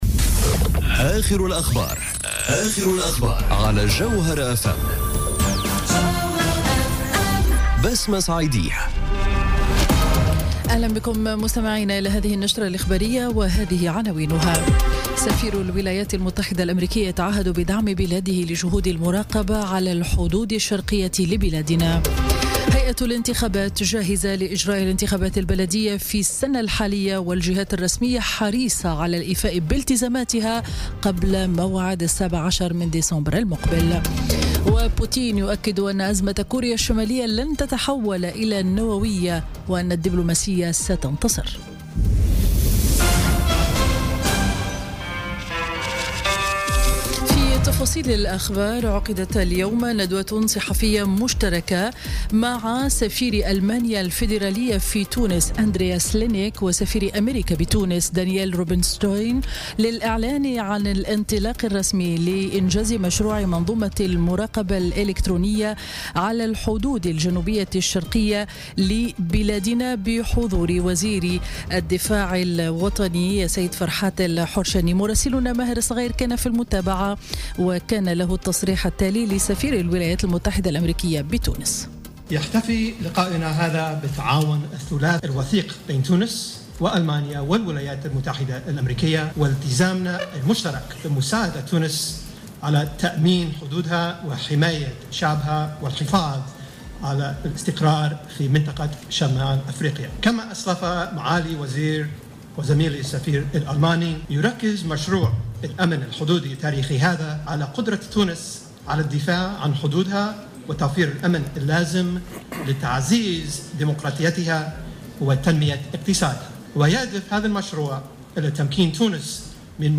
نشرة أخبار منتصف النهار ليوم الخميس 7 سبتمبر 2017